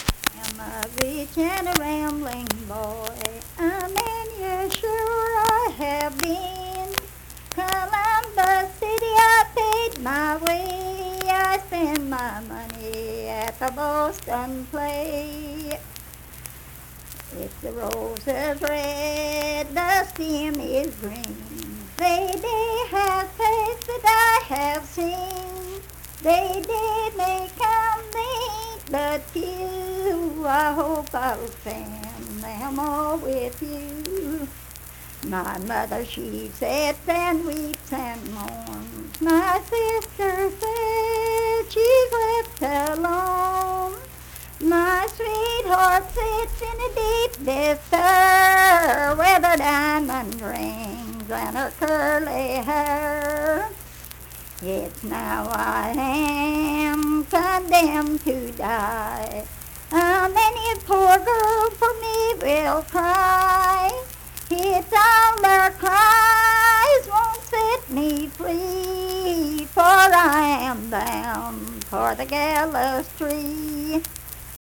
Unaccompanied vocal music performance
Verse-refrain 4(4).
Voice (sung)